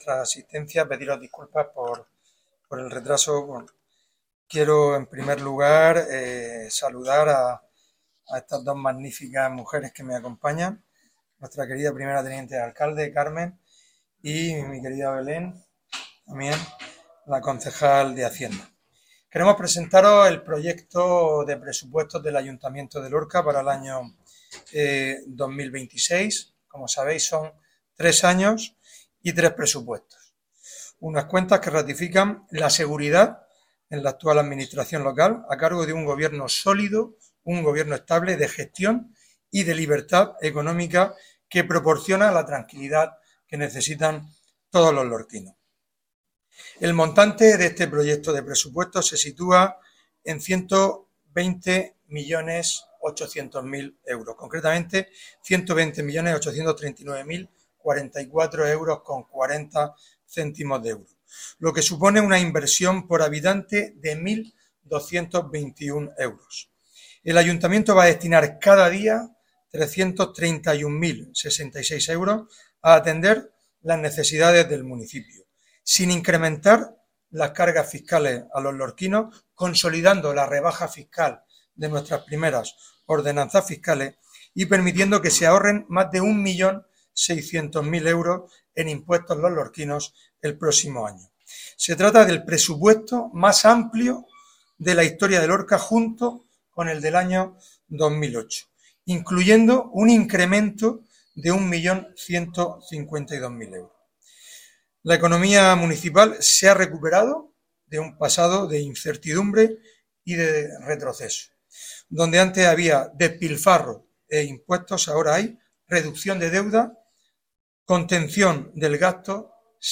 Así lo ha trasladado el alcalde de Lorca, Fulgencio Gil, junto a la primera teniente de alcalde, Carmen Menduiña y la concejal de Economía y Hacienda, María Belén Pérez, en la presentación del proyecto de presupuesto municipal para el próximo año, una previsión económica que será elevada a Pleno antes de que concluya este año para iniciar el año ya con un presupuesto aprobado, en marcha y a disposición de las necesidades de todos los lorquinos. Escuchamos a Fulgencio Gil Jodar.